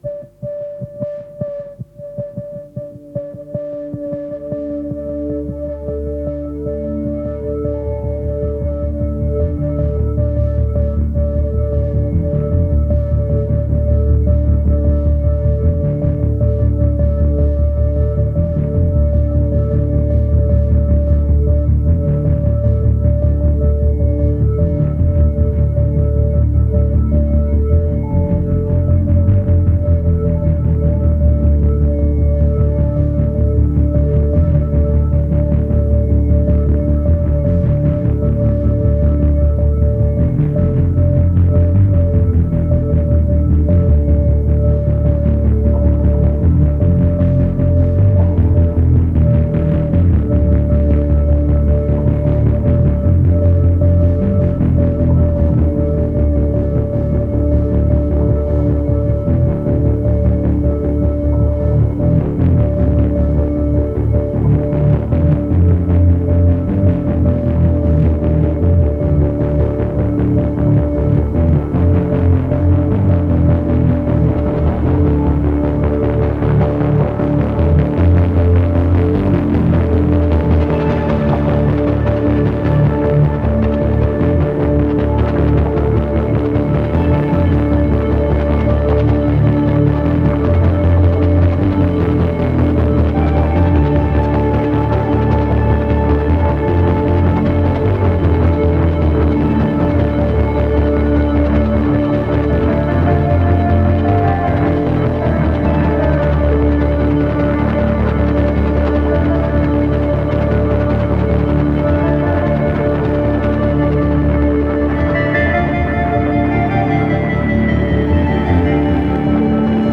Pulsating synth arpeggios and hazy textures.